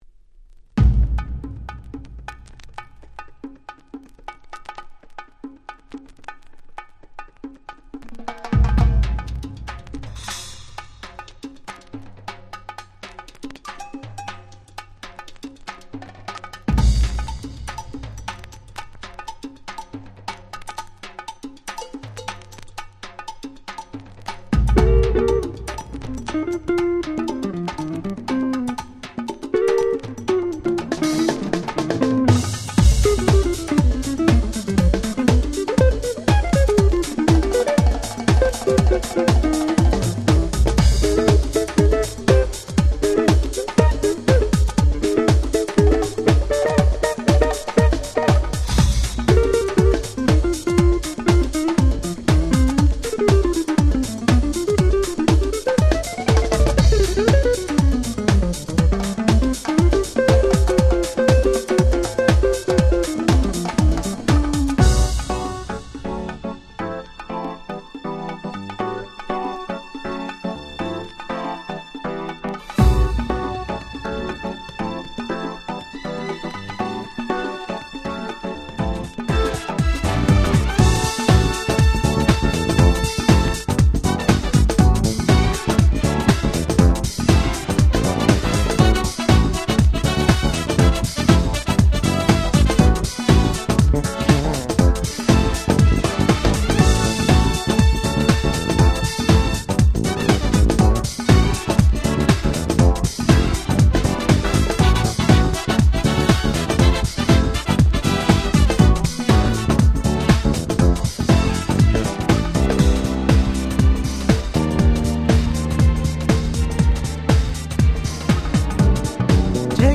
98' Nice Acid Jazz !!